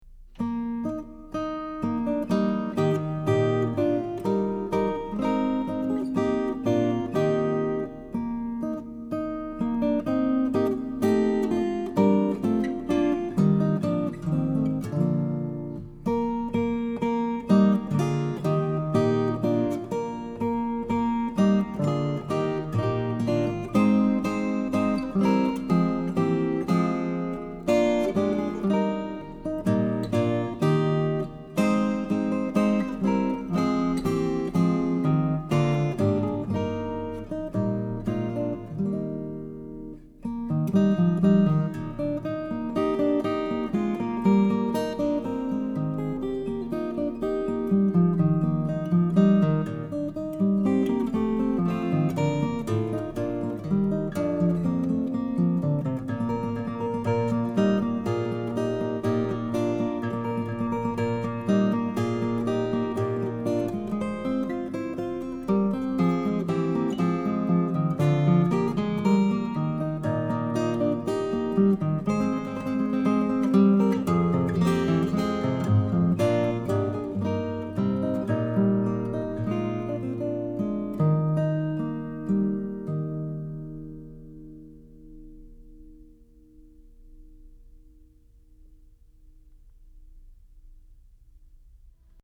DIGITAL SHEET MUSIC - CLASSICAL GUITAR SOLO